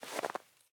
Minecraft Version Minecraft Version latest Latest Release | Latest Snapshot latest / assets / minecraft / sounds / block / powder_snow / step9.ogg Compare With Compare With Latest Release | Latest Snapshot